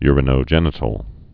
(yrə-nō-jĕnĭ-tl)